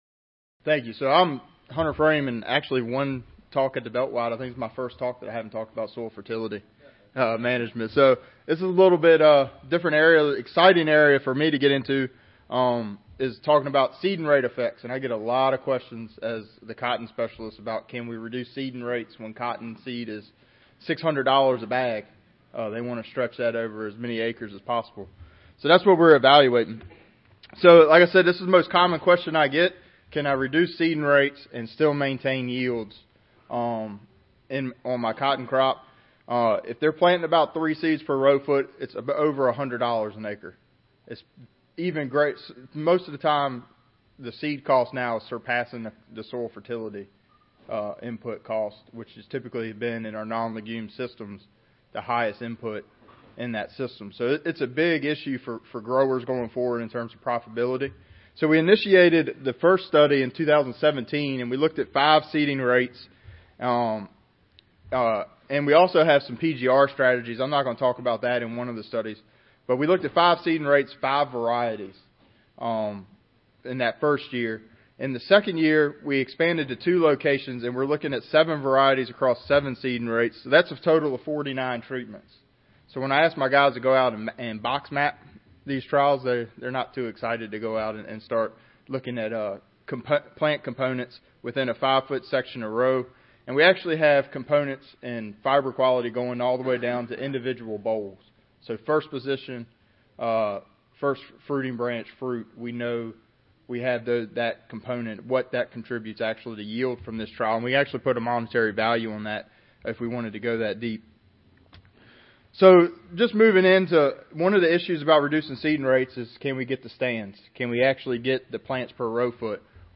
Virginia Polytechnic Institute & State University Audio File Recorded Presentation The trial was conducted as a randomized complete block design with four replication of each treatment.